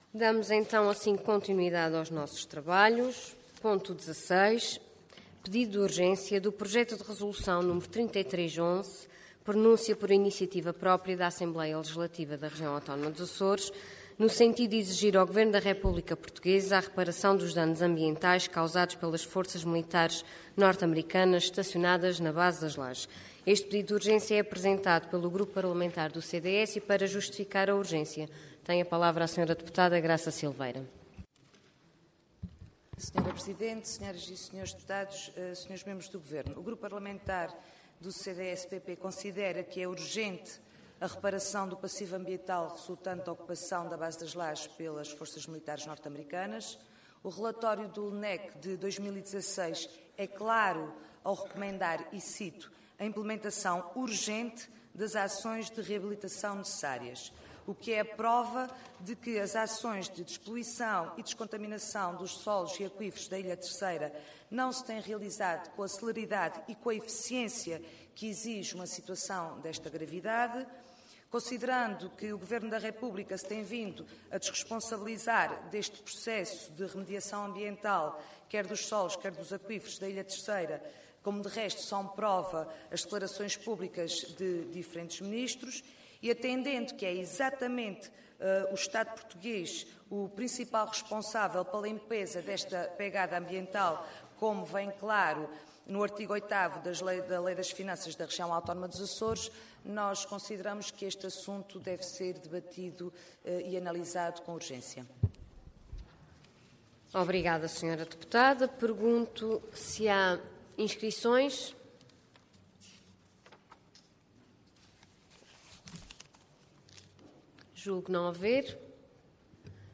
Pedido de urgência seguido de debate
Graça Silveira
Deputada